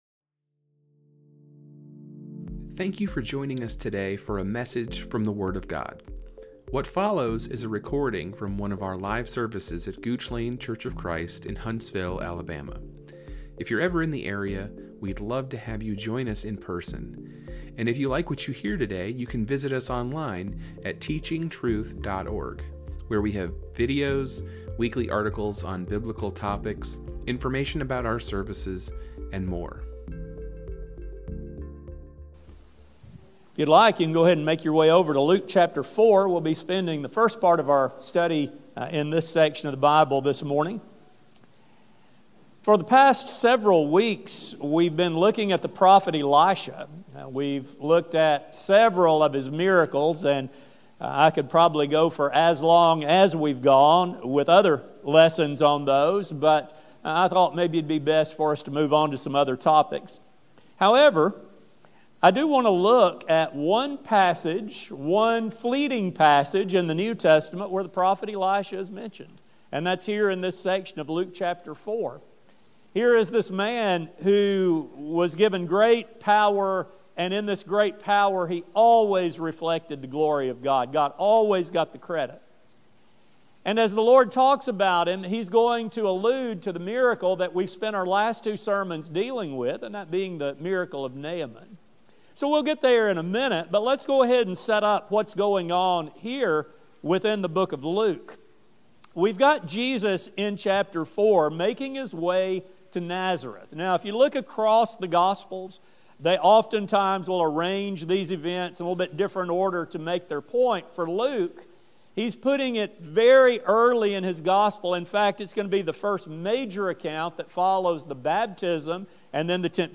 This sermon will discuss the attitude displayed by those who should have known Jesus the best and how Christians of the modern era can easily fall into this same dilemma. A sermon